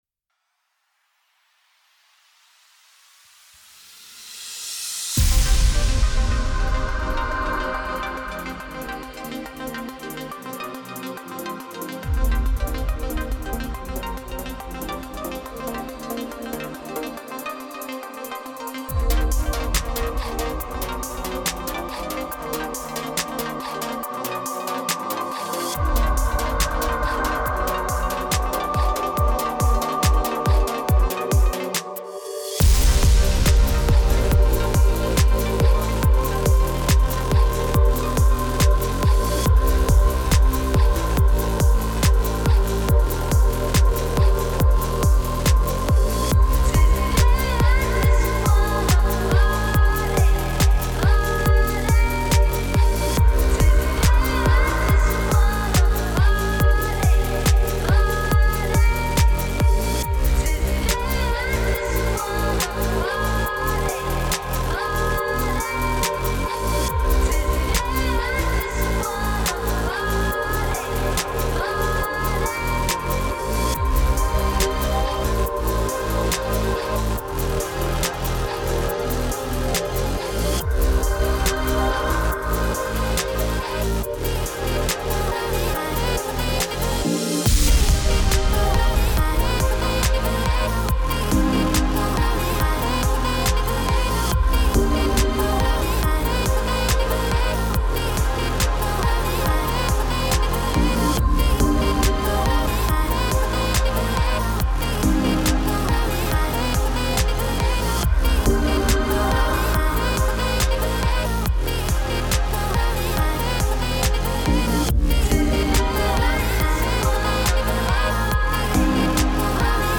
Tempo 140BPM (Allegro)
Genre EDM
Type Vocal Music
Mood energetic
Render Loudness -6 LUFS